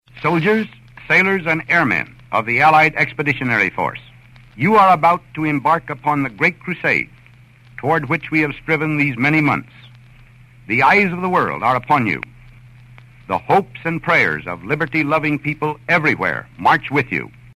The Abilene ceremony included audio of General Eisenhower delivering his Marching Order to the allied troops prior to the invasion.